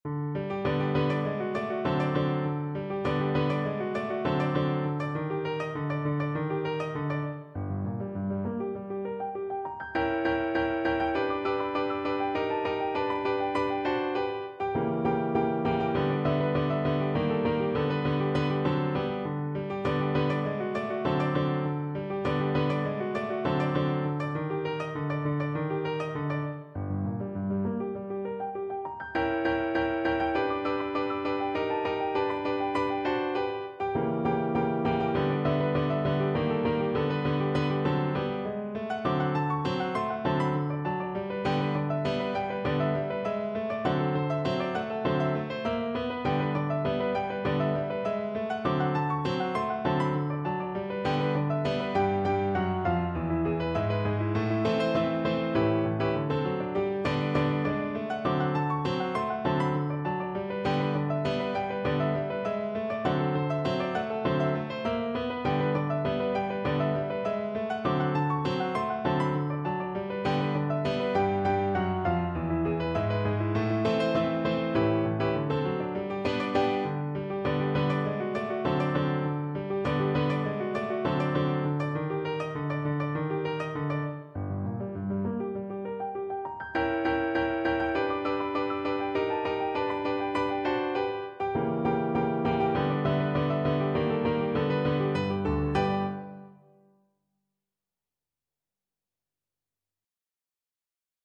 Here is an intermediate piano arrangement of Scott Joplin’s Maple Leaf Rag.
piano.maple-leaf-rag-1.mp3